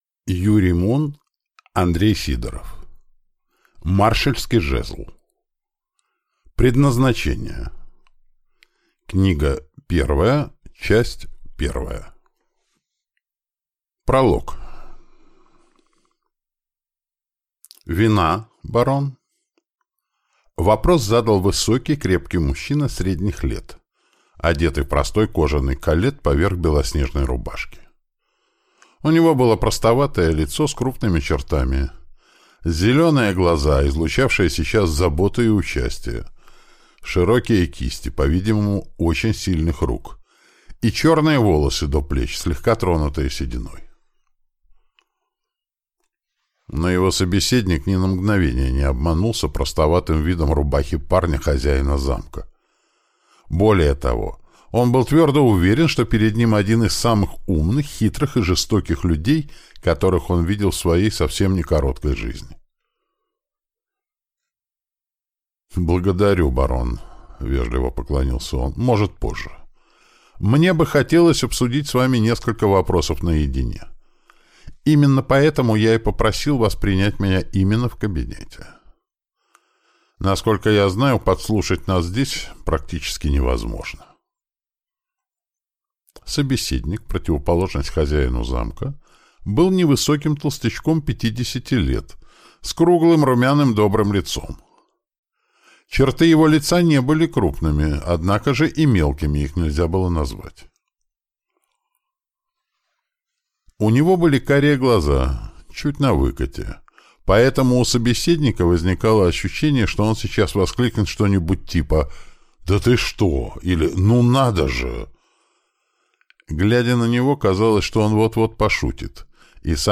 Аудиокнига Предназначение. Книга 1. Часть 1 | Библиотека аудиокниг